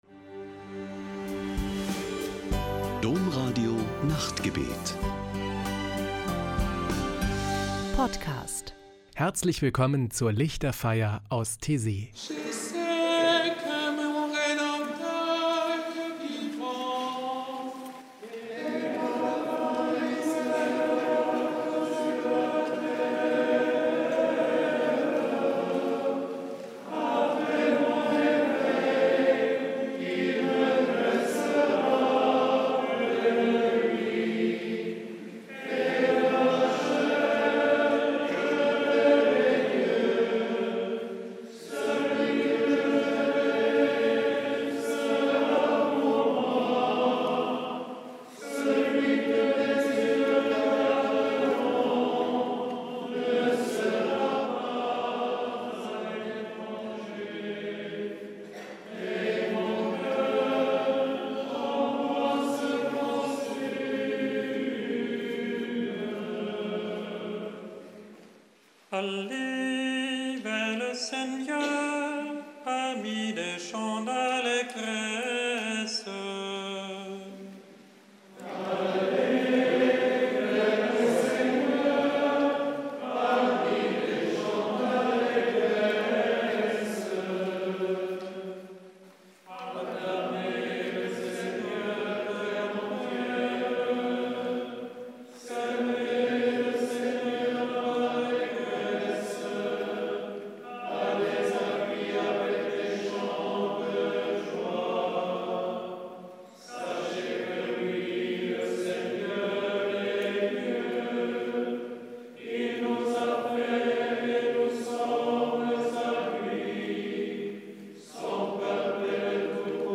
Die Lichterfeier aus Taizé: Spirituelle Gesänge und Gebete
Ein Höhepunkt jede Woche ist am Samstagabend die Lichterfeier mit meditativen Gesängen und Gebeten.